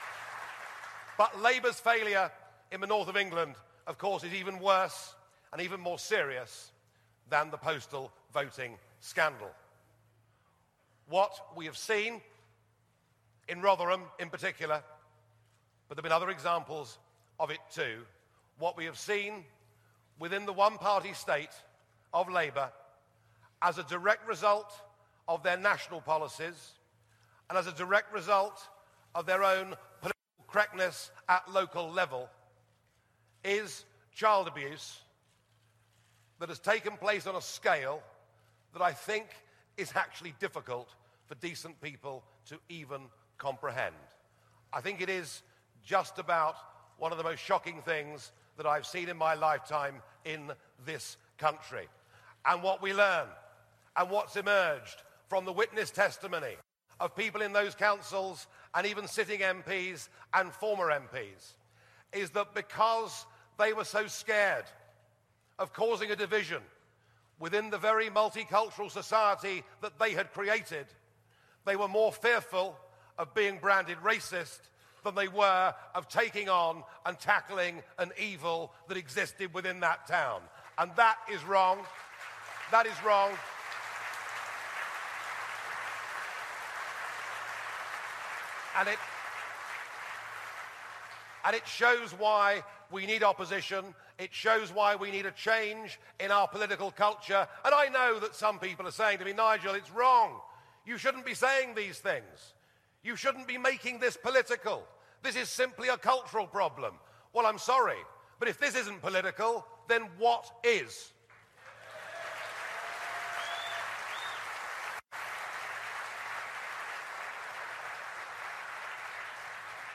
Ukip conference, 26 September 2014